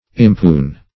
impune - definition of impune - synonyms, pronunciation, spelling from Free Dictionary Search Result for " impune" : The Collaborative International Dictionary of English v.0.48: Impune \Im*pune"\, a. [L. impunis.]